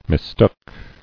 [mis·took]